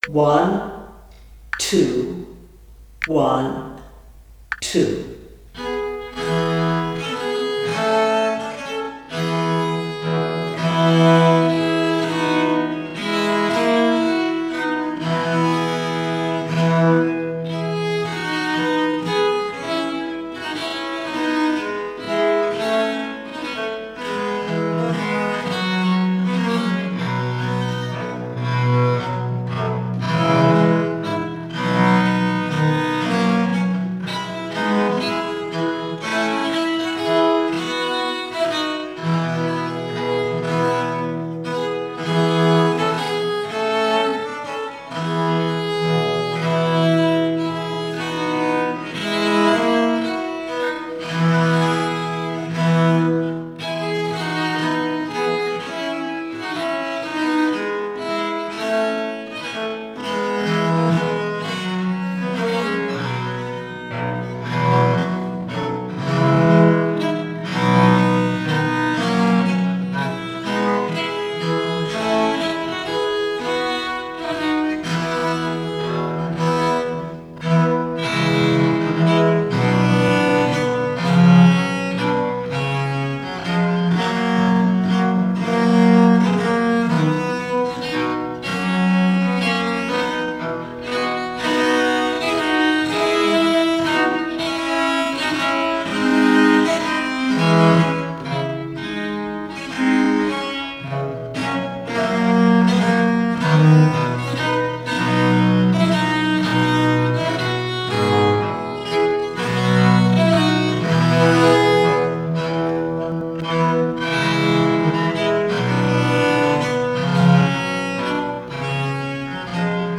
Ferrabosco coranto, real slow, both parts
Ferr-coranto-mm40.mp3